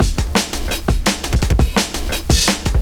cw_170_Chopper.wav